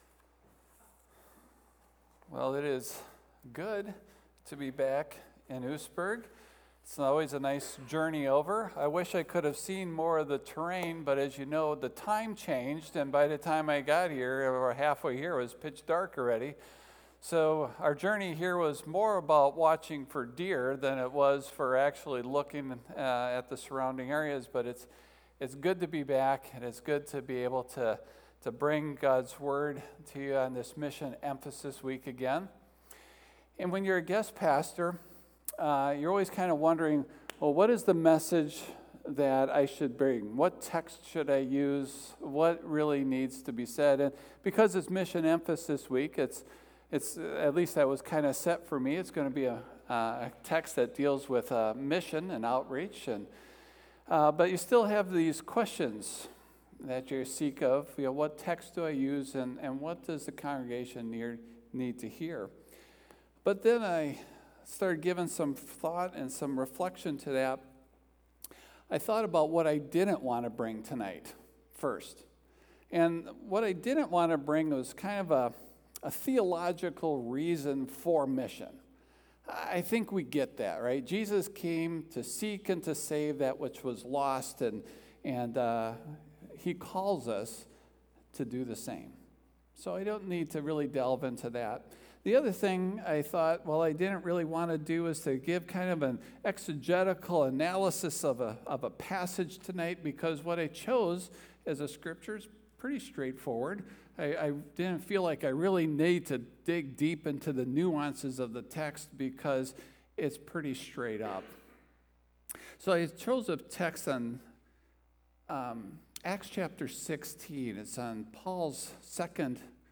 Service Type: PM
Sermon+Audio+-+What+Now,+God.mp3